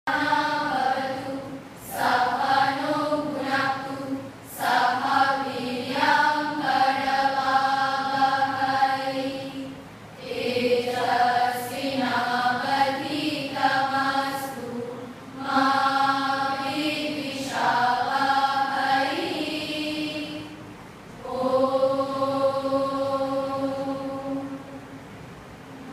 There was no harmonium or any musical instrument to support them, but they sang in melodious voices “Madhuvata Rutayate,”  “Sahana Vavatu…,” a hymn on Sri Sarada Devi, and a Bengali song on Sister Nivedita.
The following are small clips which give some idea of their singing: